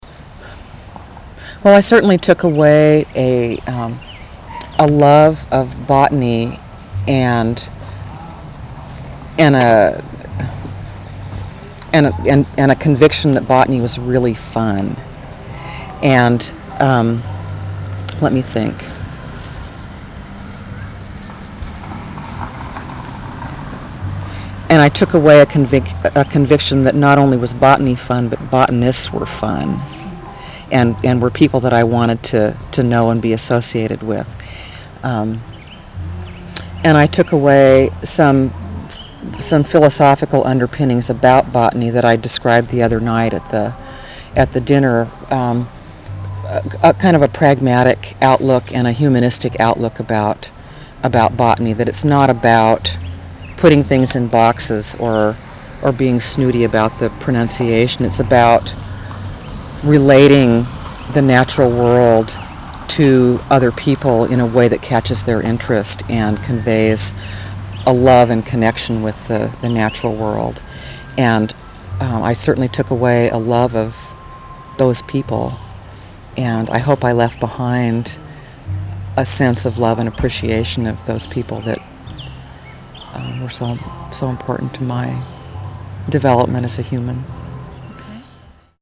Summary of Interview
Location: Mt. Pisgah Arboretum, Eugene, Oregon